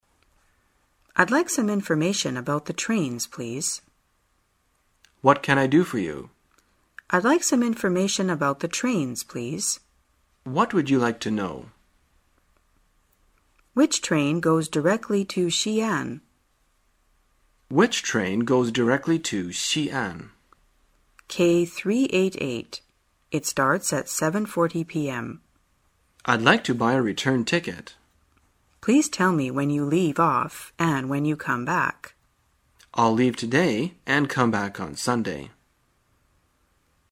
在线英语听力室生活口语天天说 第94期:怎样了解列车信息的听力文件下载,《生活口语天天说》栏目将日常生活中最常用到的口语句型进行收集和重点讲解。真人发音配字幕帮助英语爱好者们练习听力并进行口语跟读。